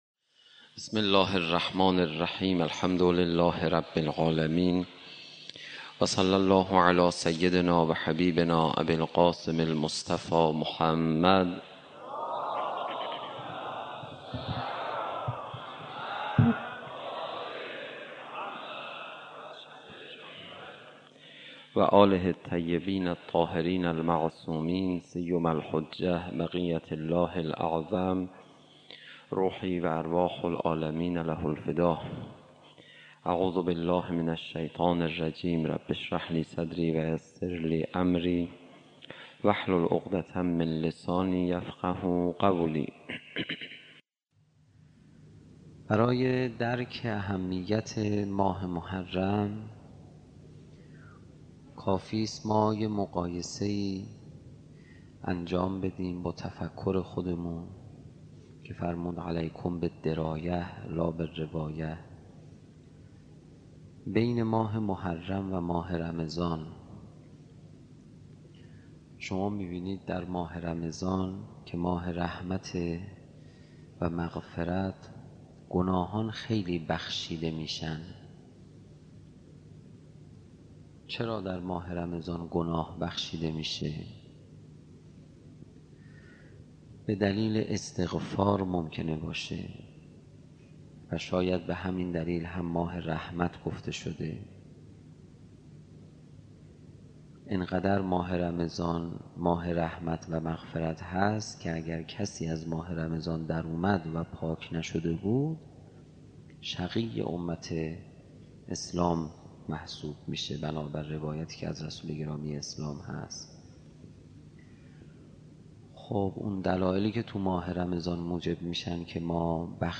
دانلود کلیپ دانلود فایل کامل سخنرانی